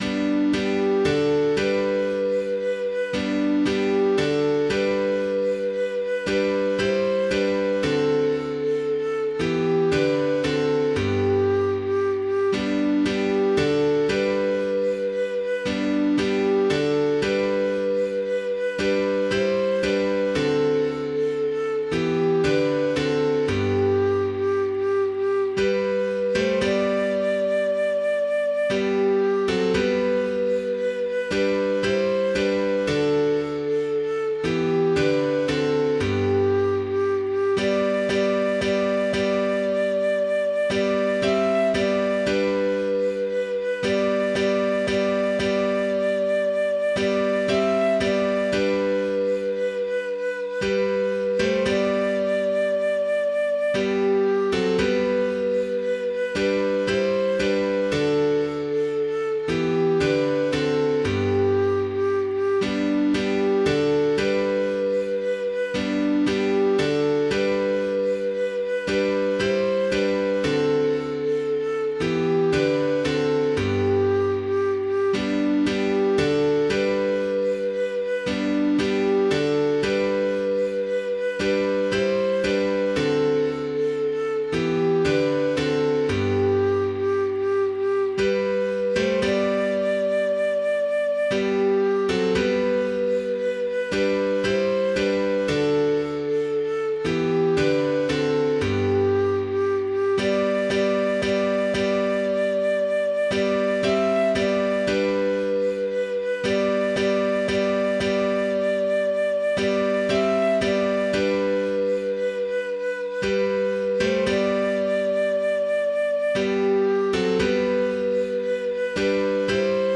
piano, keyboard, keys
Мелодия за разучаване: